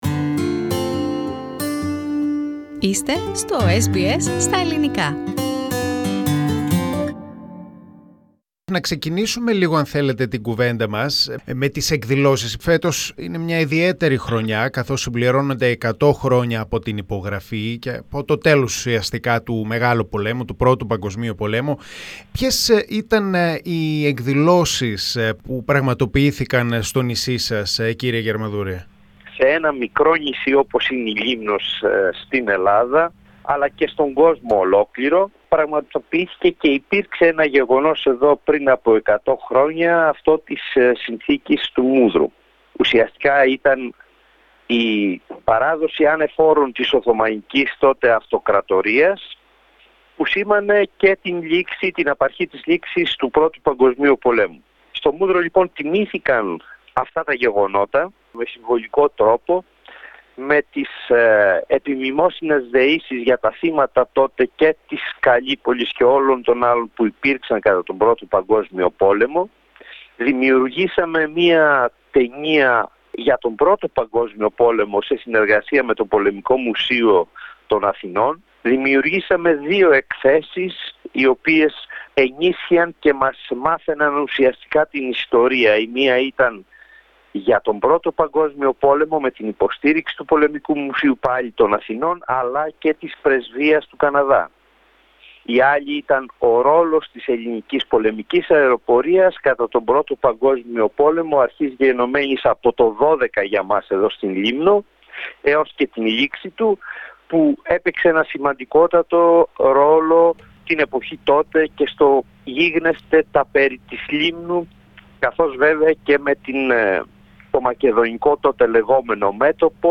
Δεκάδες εκδηλώσεις στη Λήμνο για την εκεχειρία του Μυδρού και την 100ή επέτειο από τη λήξη του Πρώτου Παγκοσμίου Πολέμου. Ο Έπαρχος Λήμνου, Βαγγέλης Γιαρμαδούρος, μίλησε στο Ελληινκό Πρόγραμμα.